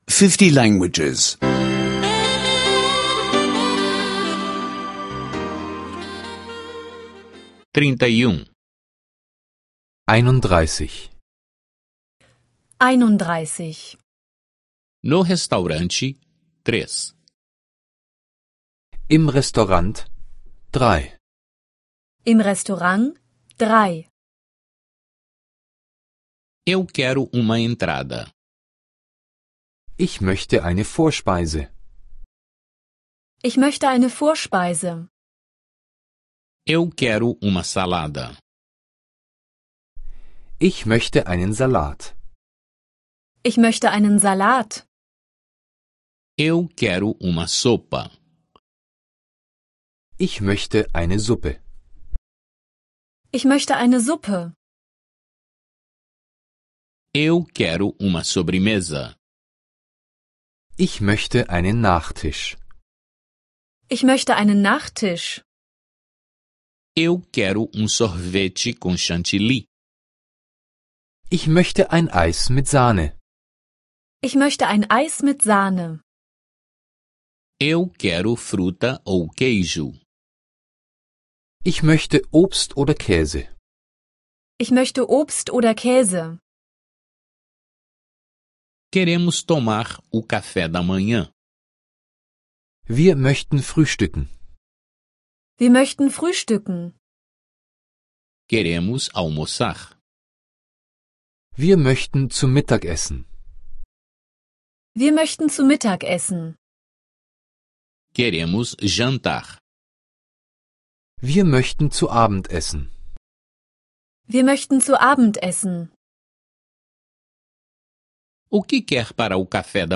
Aulas de alemão em áudio — escute online